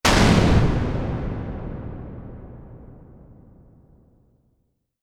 Jumpscare_05.wav